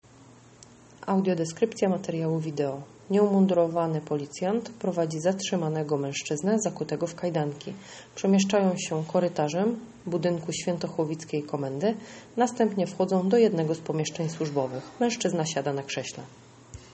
Nagranie audio audiodeskrypcja materiału wideo